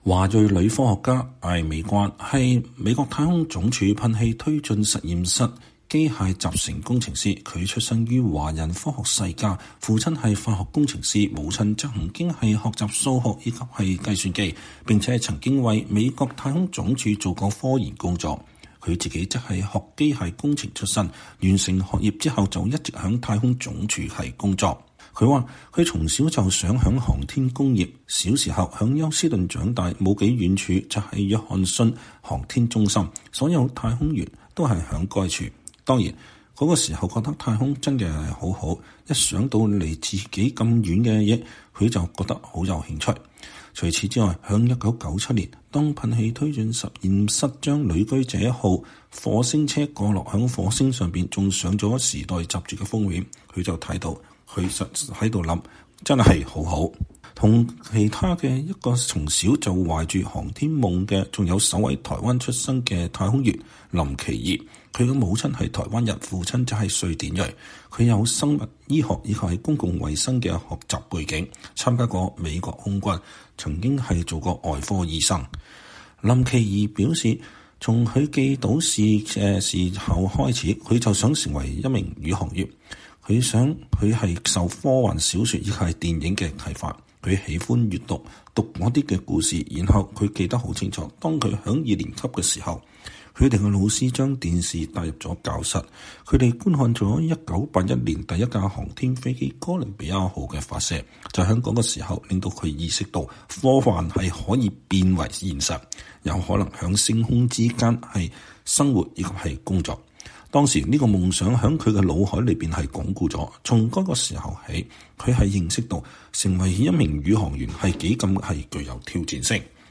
專訪NASA華裔科學家：美國航天事業強大的原因何在